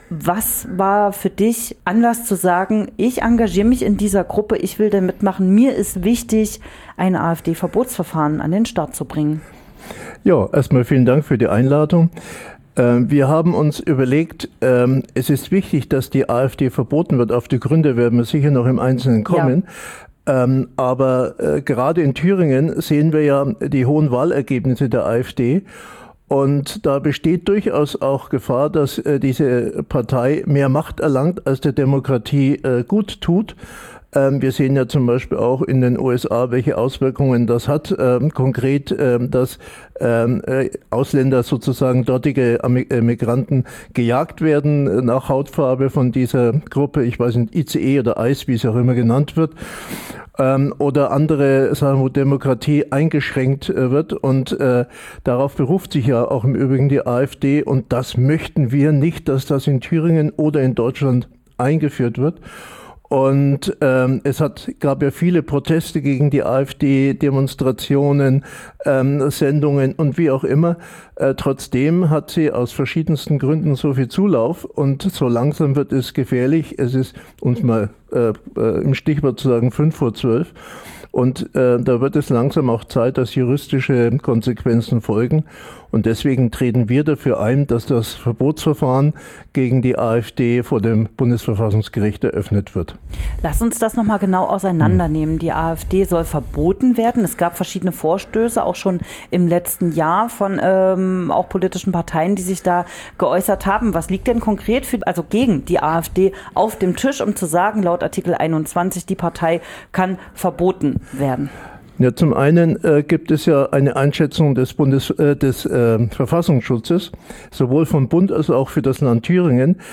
mit ihm sprachen wir über die Gründe und Vorhaben.